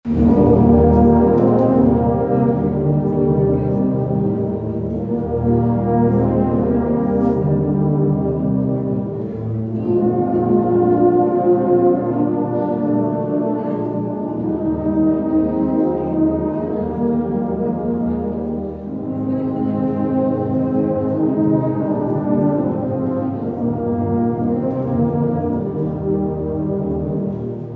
Dozens of tuba and euphonium players gathered at Manhattan Town Center Saturday for TubaChristmas.